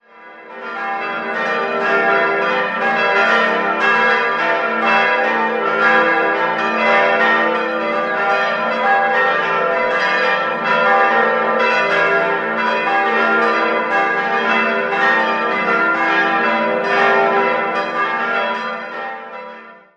Die Glocken 2, 4 und 5 wurden 1768 von Joseph Arnold gegossen, die mittlere Glocke stammt von Kuhn-Wolfart in Lauingen (1949) und die große schuf im Jahr 1959 Karl Czudnochowsky in Erding.